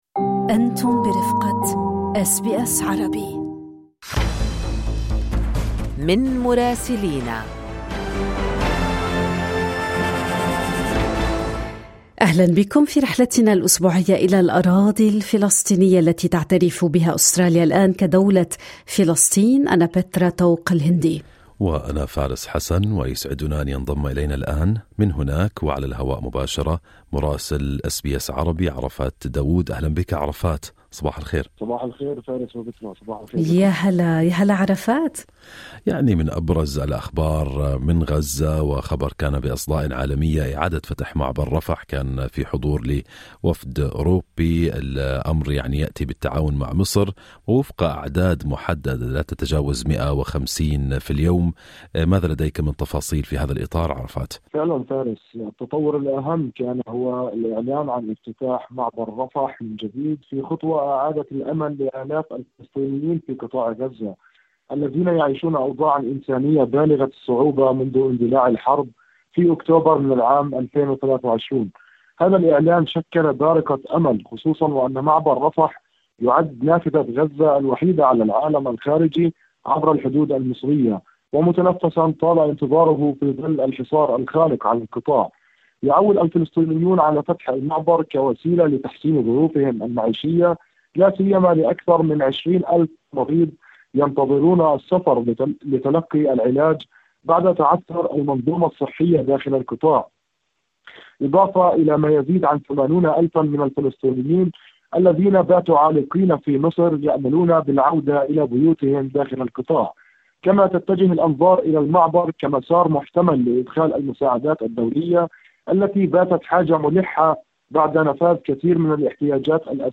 تقرير مراسلنا في رام الله يتناول تطورات ميدانية وإنسانية متسارعة في غزة والضفة الغربية. فقد أُعلن عن إعادة فتح معبر رفح بآلية محدودة وتحت إشراف دولي، وبالتنسيق مع مصر، ما أعاد الأمل لآلاف الفلسطينيين في قطاع غزة، خصوصًا المرضى والعالقين في الخارج.